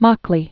(mäklē), John William 1907-1980.